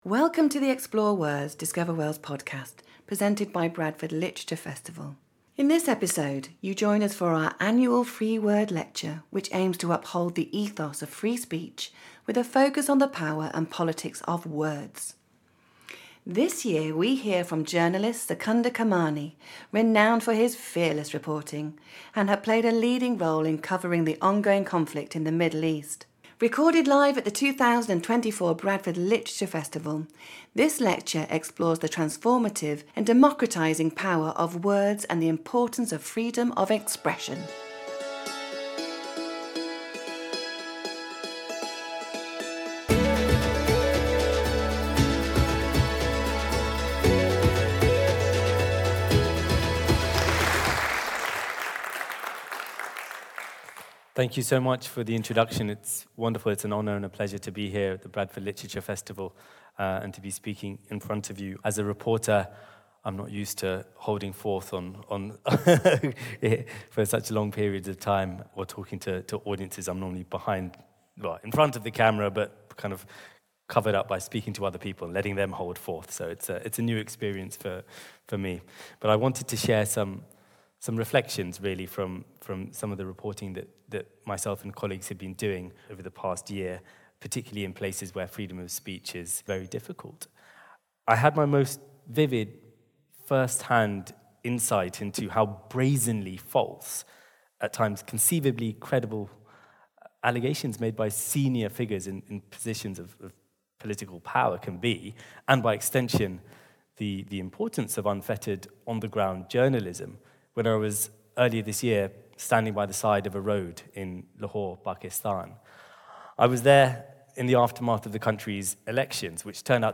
Free Word Lecture (; 06 Aug 2025) | Padverb
Join us for our annual Free Word Lecture, which aims to uphold the ethos of free speech with a focus on the power and politics of words.